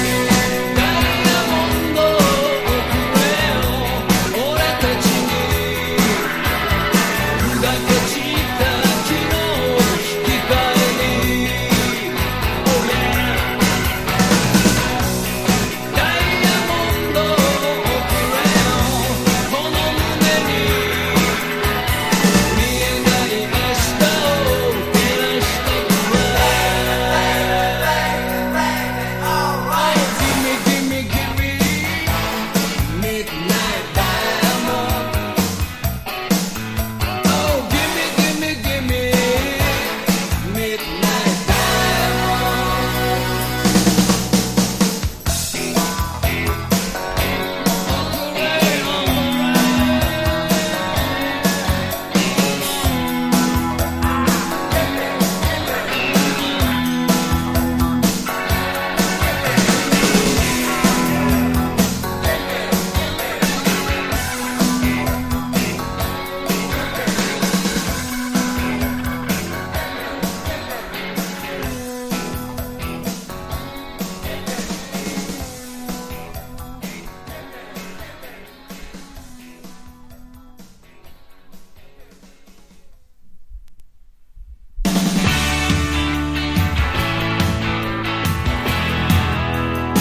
# 60-80’S ROCK